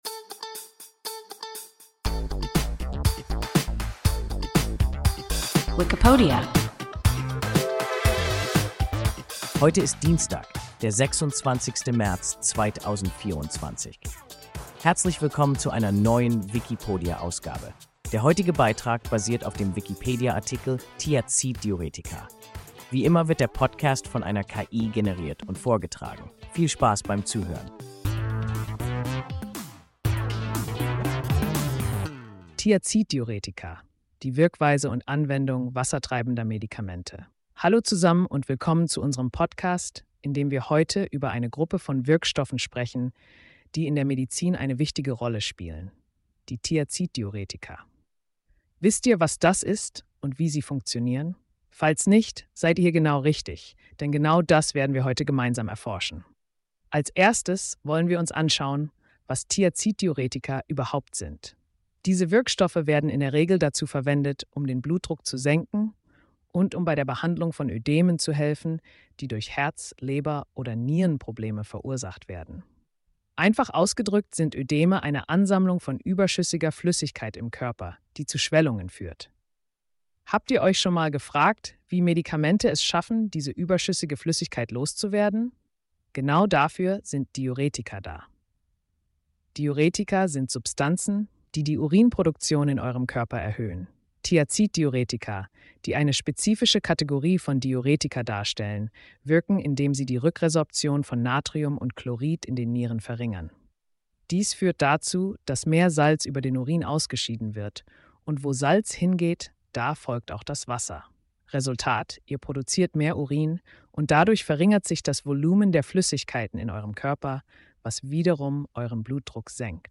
Thiaziddiuretika – WIKIPODIA – ein KI Podcast